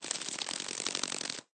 beetle_wings.ogg